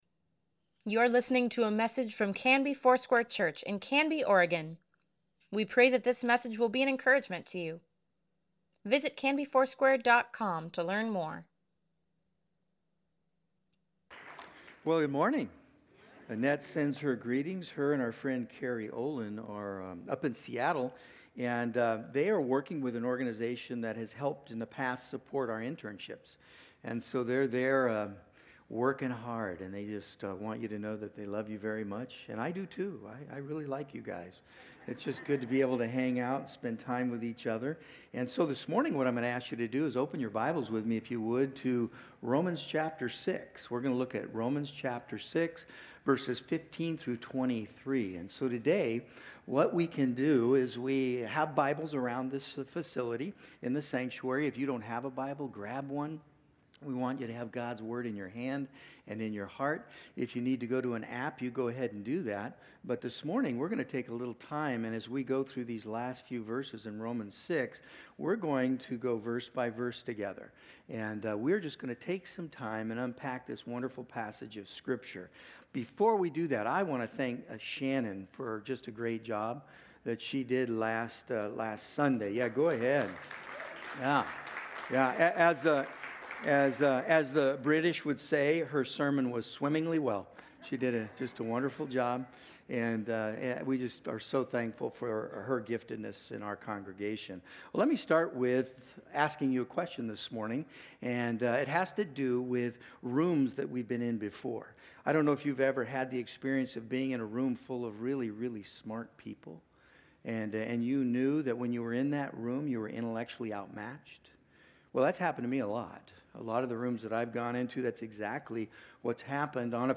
Sunday Sermon | May 19, 2024